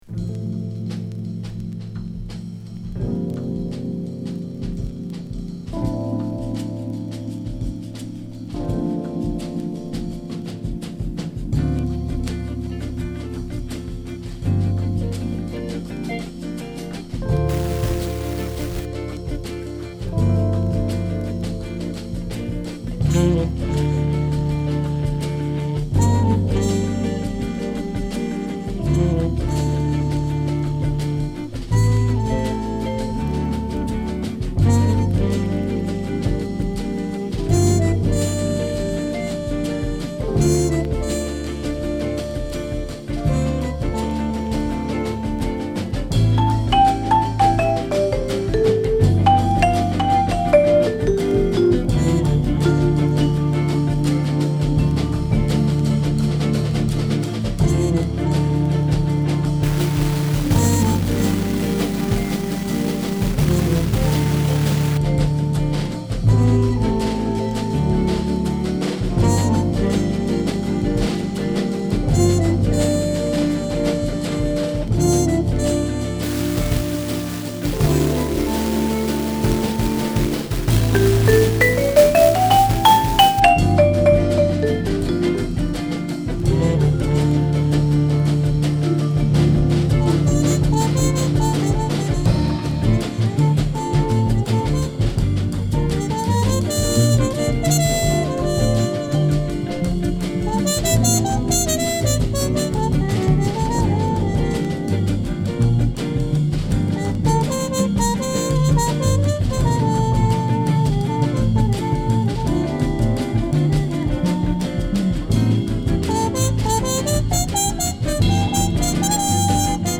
ファンキーな曲からメロウな曲まで目白押し。